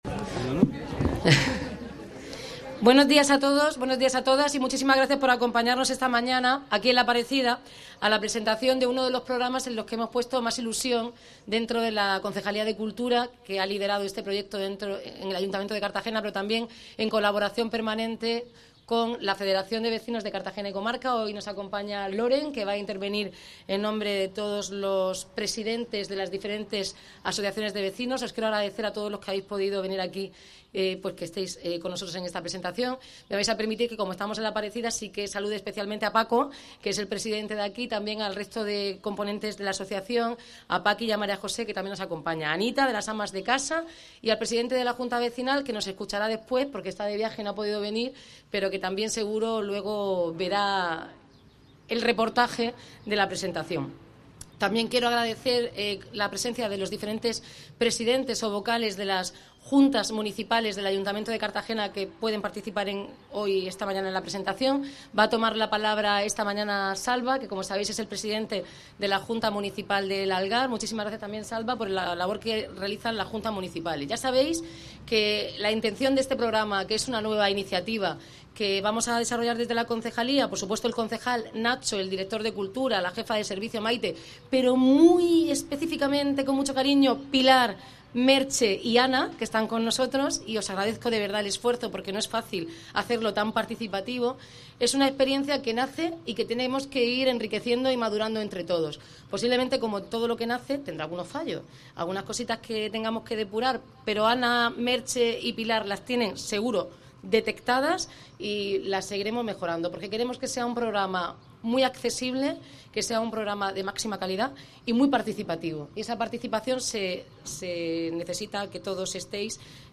Declaraciones de la alcaldesa, Noelia Arroyo